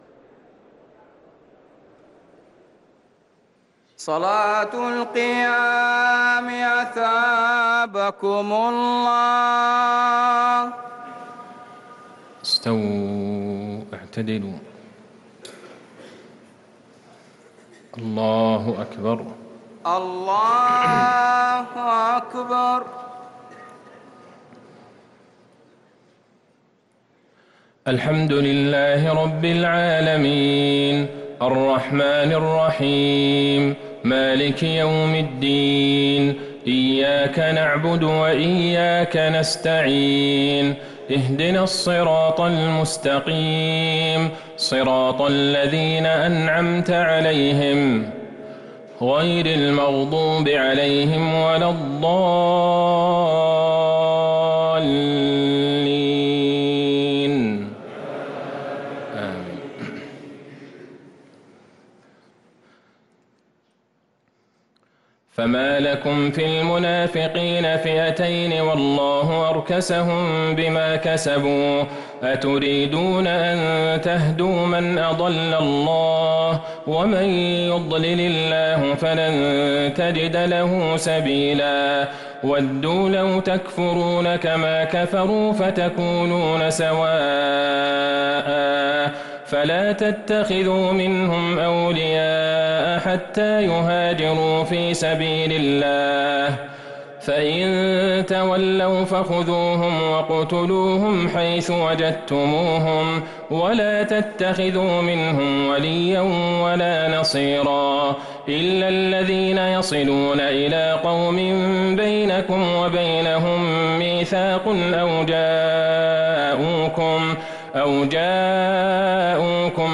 صلاة التراويح ليلة 7 رمضان 1444 للقارئ عبدالله البعيجان - الثلاث التسليمات الأولى صلاة التراويح